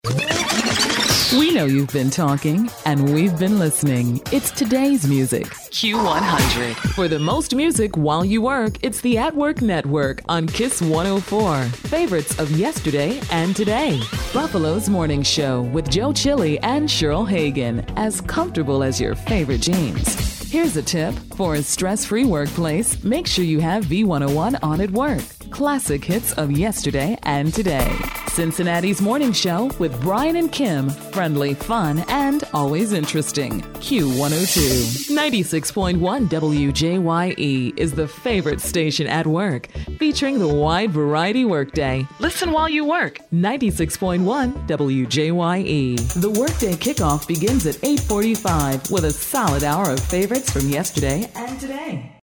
englisch (us)
Sprechprobe: Sonstiges (Muttersprache):
A voice with true range, excellent natural characters, sympathetic, everyday person. My sound is also as sensual as melted chocolate.